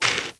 SPADE_Dig_04_mono.wav